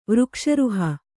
♪ vřkṣa ruha